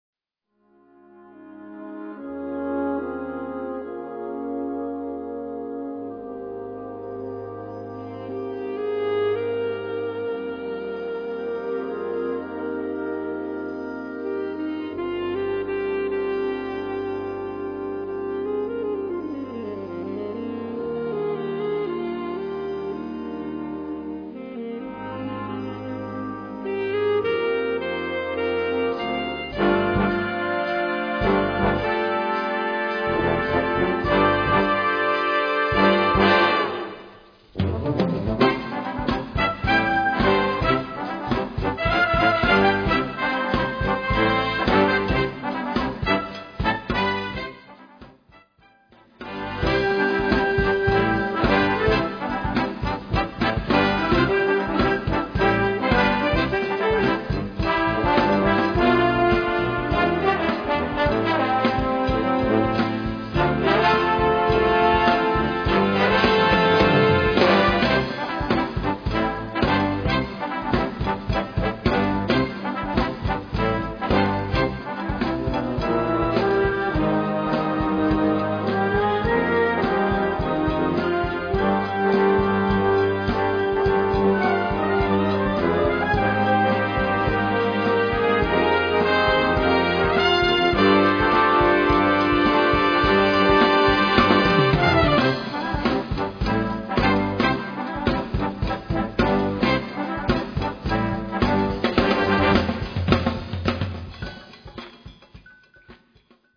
Dies ist ein grooviges Stück das alle vom Hocker reisst!
Gattung: Moderne Blasmusik
Besetzung: Blasorchester